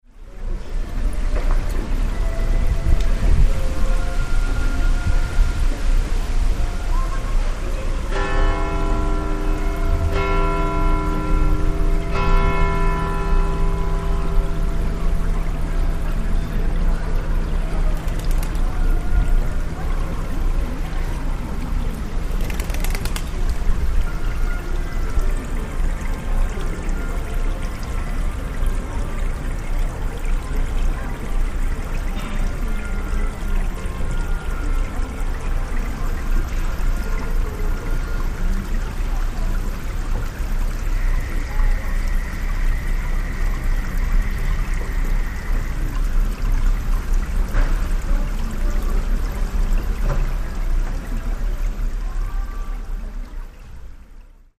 The bell tower of the Hotel de Ville in Aix-en-Provence, France has been active since the 1500's. In January, 2006 I made binaural recordings of the bells on the hour at 12 locations determined by clock overlay on the town map above. In between the hours are random auditory scenes recorded throughout the town - each space with its own sound signature.